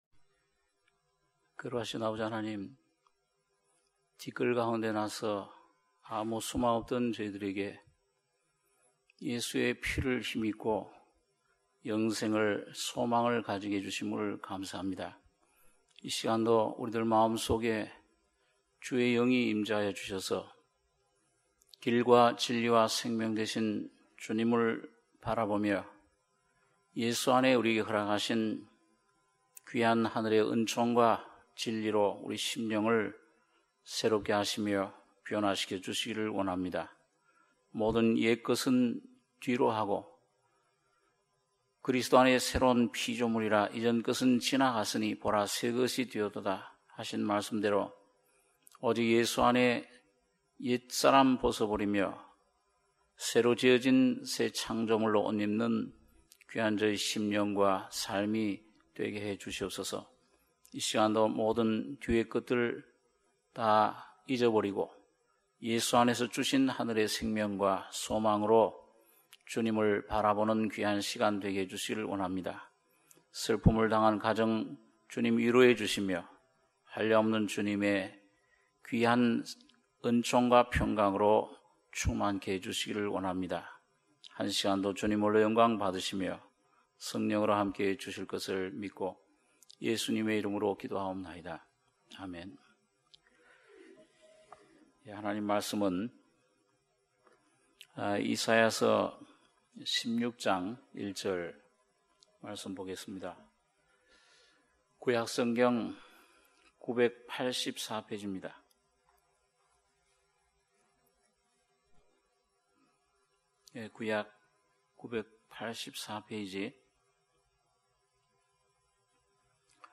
수요예배 - 이사야 16장 1절-10절